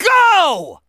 • The "GO!" voice line heard from Sonic's phone when he starts the race is from Jason Griffith in Sonic Unleashed (2008).